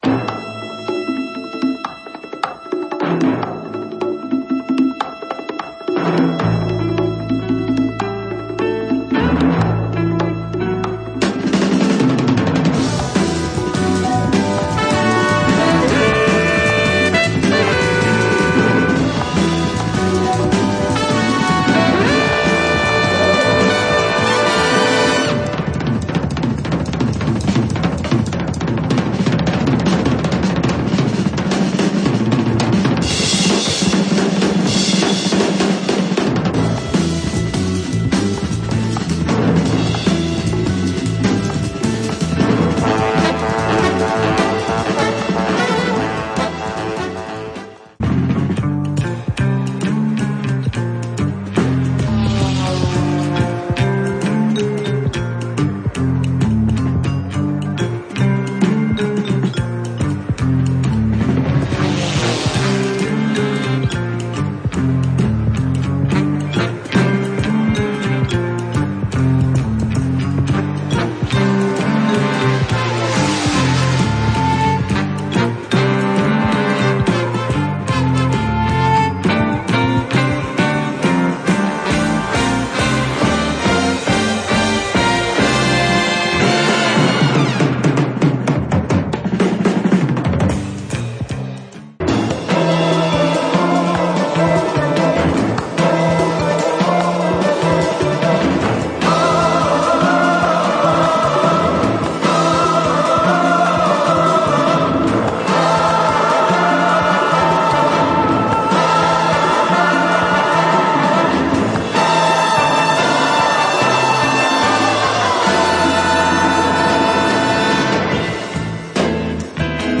AccueilMusiques de films  >  Illustration Sonore